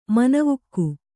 ♪ manavukku